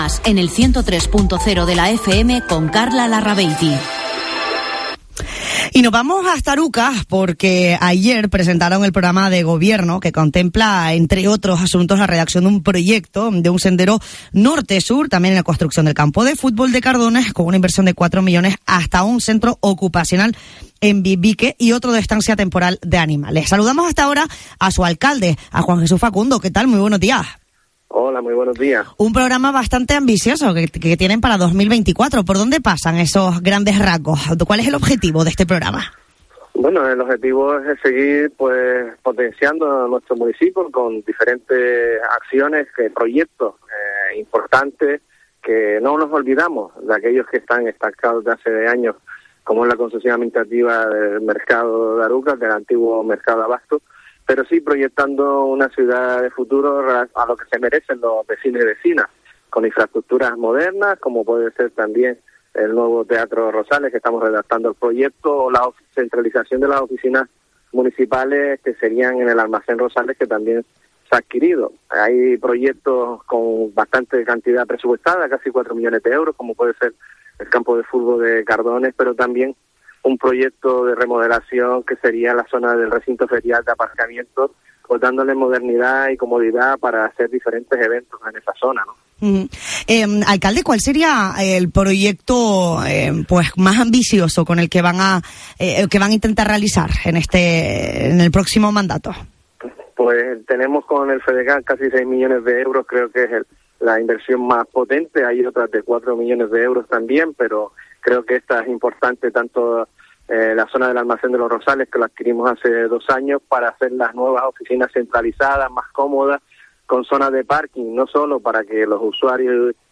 Entrevista a Juan Jesús Facundo, alcalde de Arucas, sobre el nuevo programa municipal